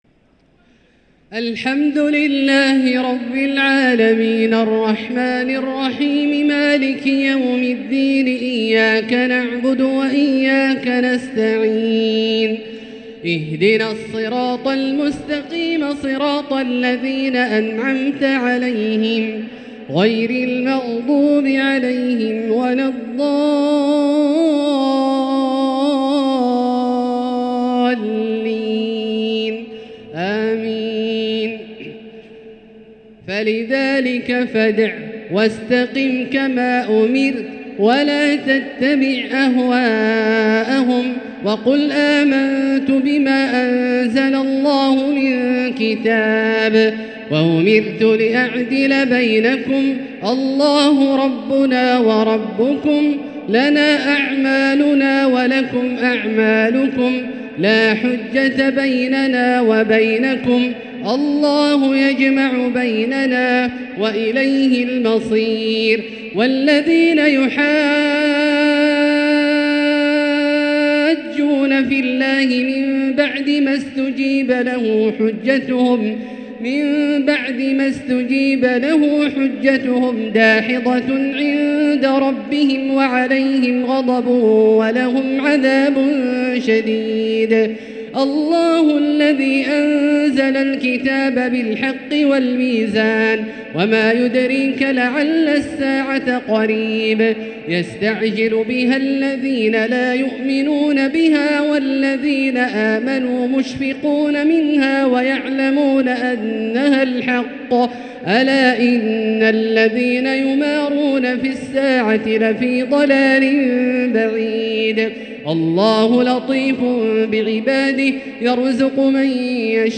تهجد ليلة 26 رمضان 1444هـ| سورة الشورى (15-35) الزخرف والدخان | Tahajjud 26 st night Ramadan 1444H Al-Shura & Az-Zukhruf & AdDukhan > تراويح الحرم المكي عام 1444 🕋 > التراويح - تلاوات الحرمين